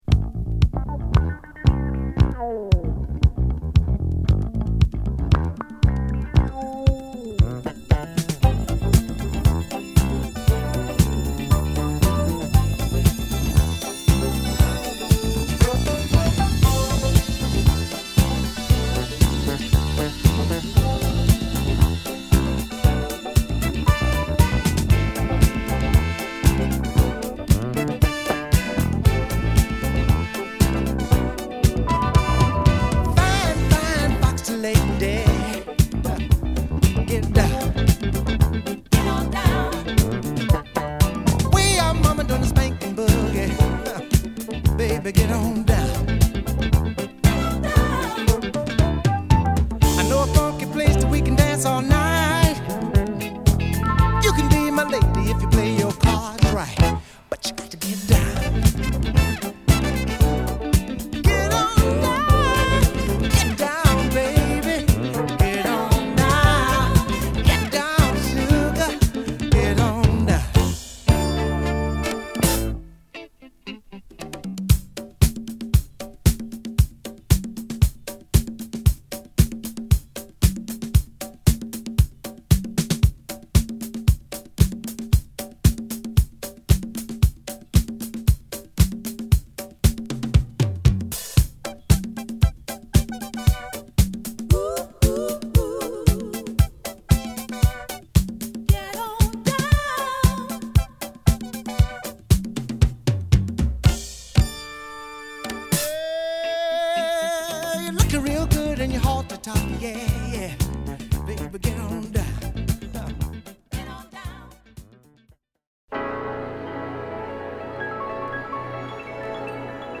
メロウからダンストラックまで見逃せません！...